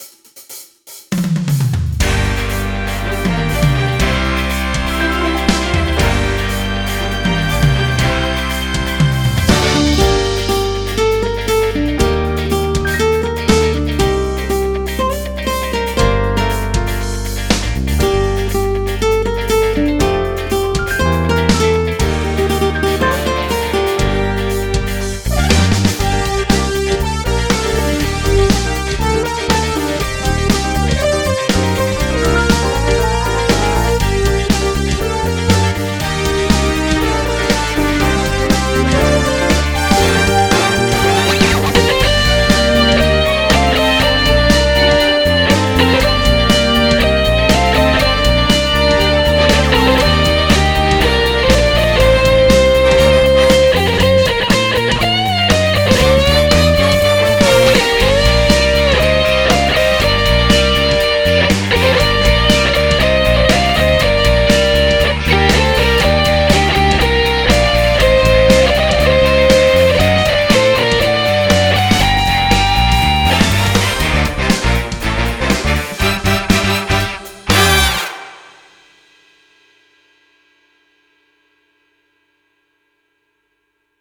יש בריחה מהקצב +תו אחד שמזייף בדקה 1:07-1:08